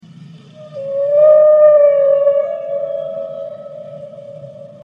На этой странице собраны их уникальные вокализации: от низкочастотных стонов до резких щелчков эхолокации.
Вой кашалота в глубинах океана